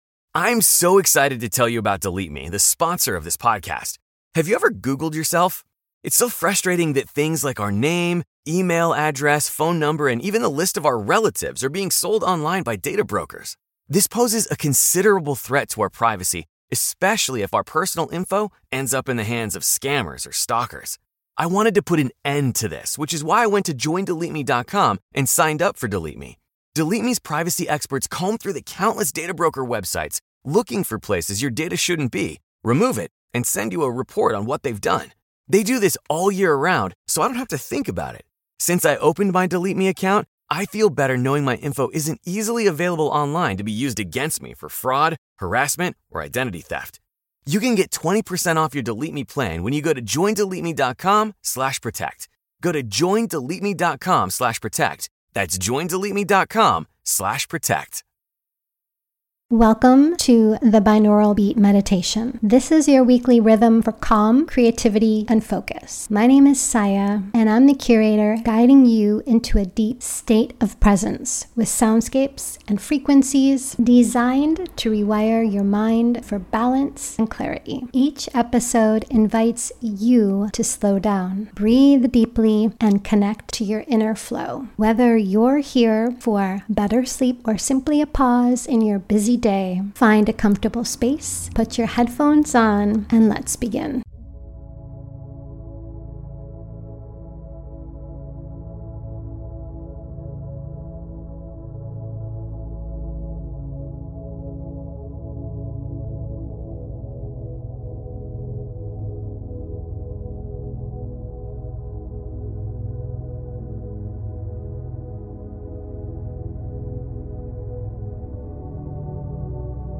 10hz - Alpha Waves for the New Moon - Mental Clarity + Gentle Focus
Binaural Beats Meditation for Sleep